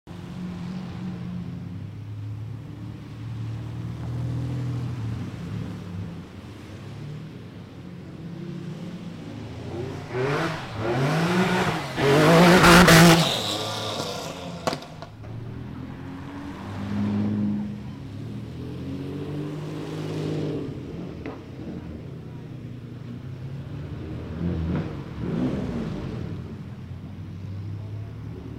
Lamborghini Diablo SV-R Drift 😱